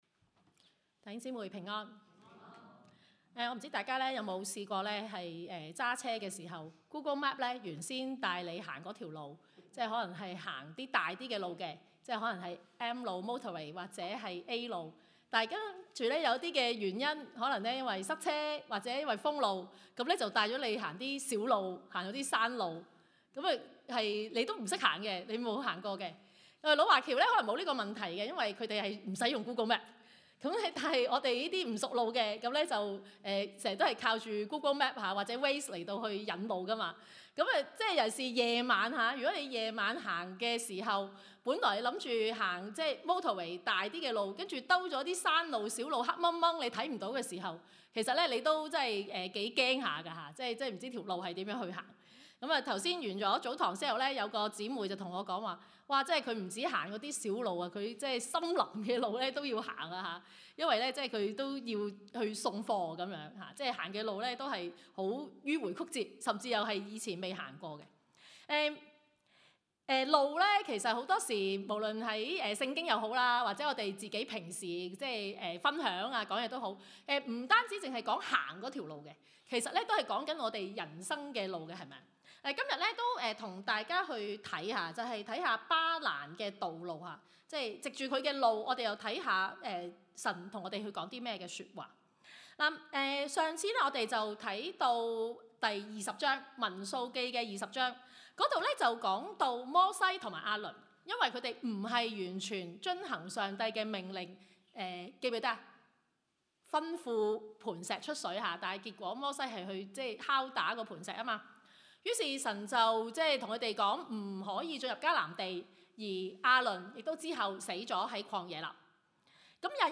Passage: 路加福音 24:13-35 Service Type: 粵語崇拜